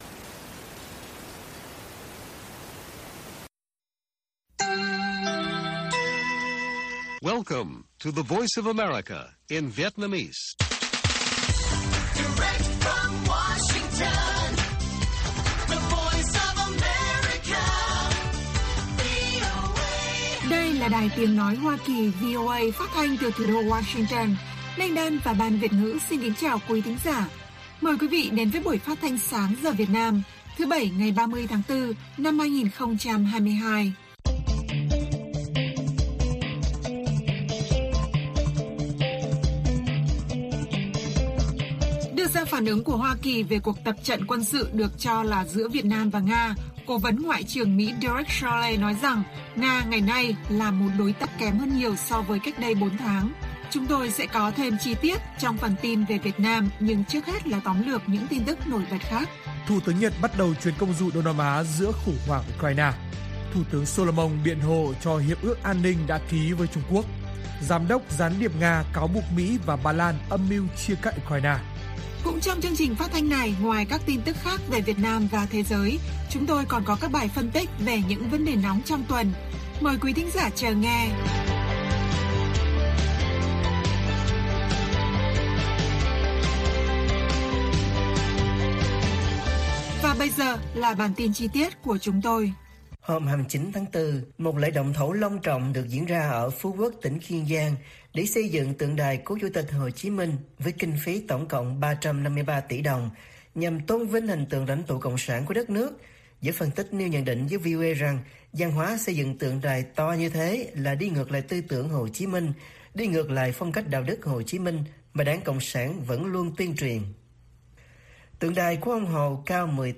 Mỹ phản ứng về tin Nga sắp tập trận với Việt Nam - Bản tin VOA